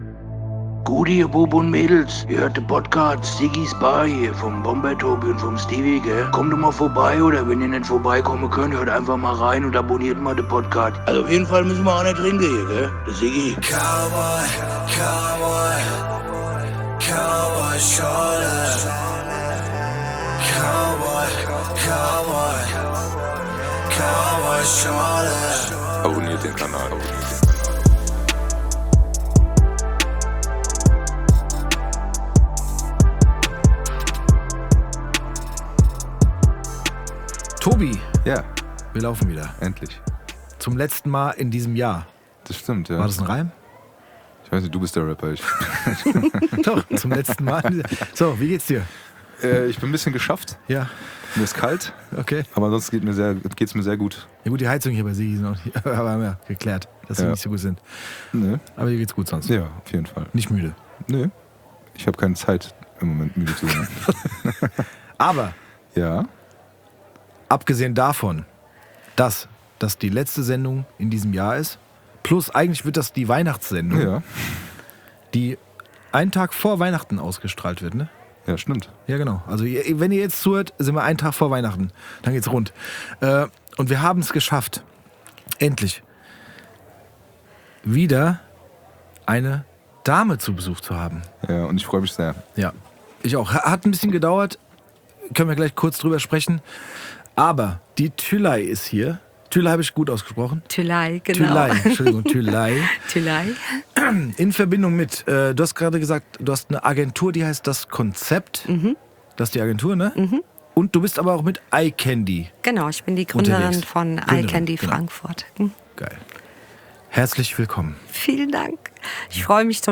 Sie hat eine sehr angenehme Mikrofon-Stimme und das kommt nicht von ungefähr.